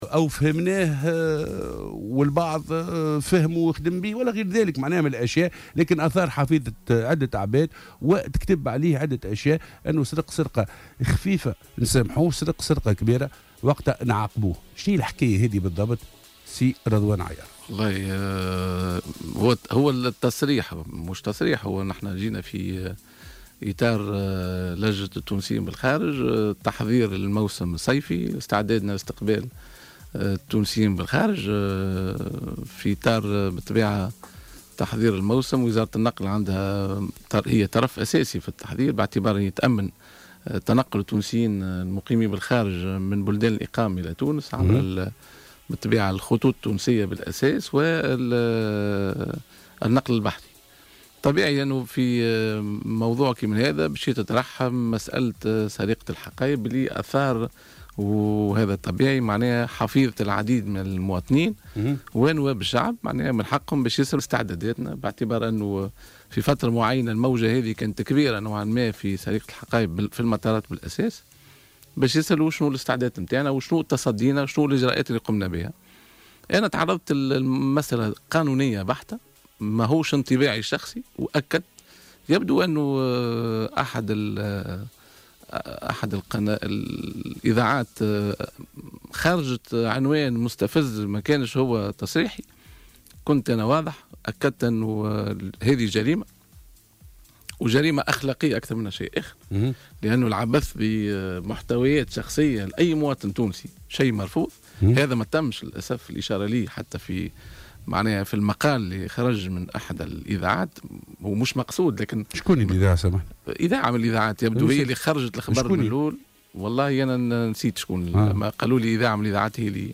وأوضح ضيف "صباح الورد" في ستوديو "الجوهرة اف أم" بتونس العاصمة، أنه لم يدل بتصريح حول هذه المسالة وكان الأمر في إطار اجتماع مع لجنة التونسيين بالخارج البرلمانية وتم خلاله التتطرّق لسرقة أمتعة المسافرين.